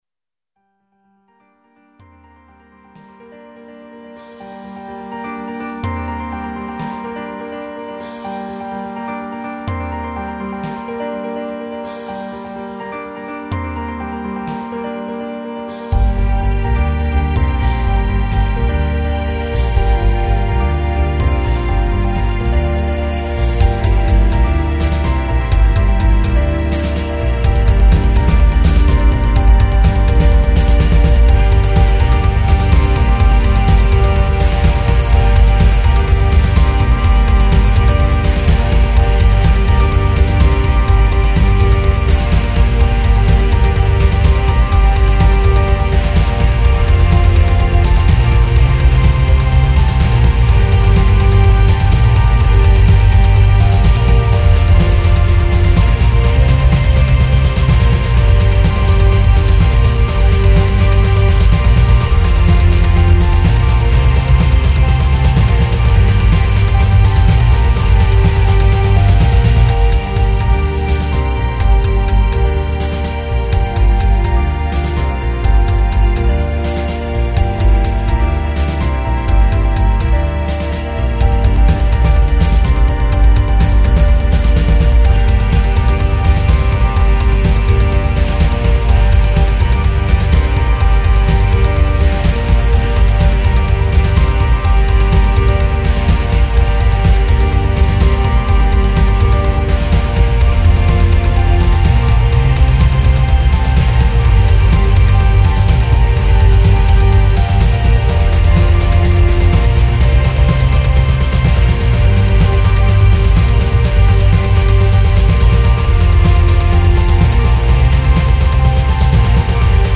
Rock
'05 Remix EQ